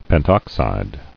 [pent·ox·ide]